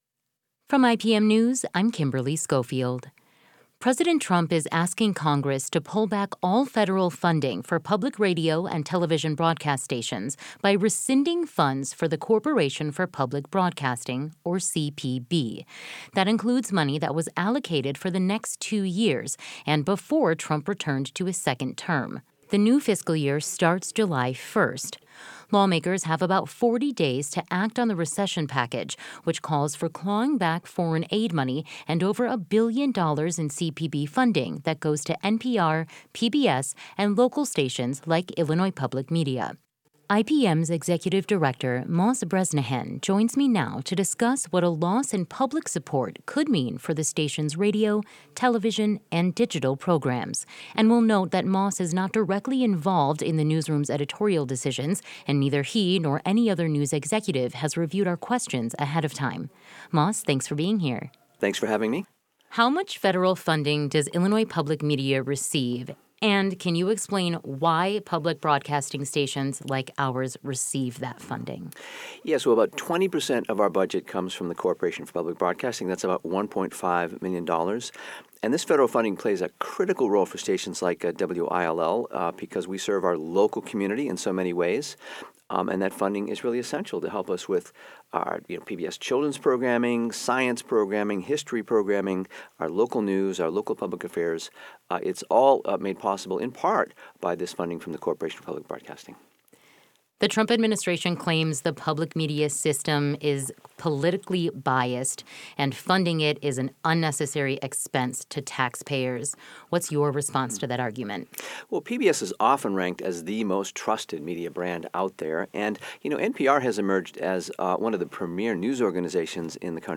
Editor’s Note: Per Illinois Public Media’s internal reporting guidelines, no member of upper management or news executive reviewed this interview prior to publication.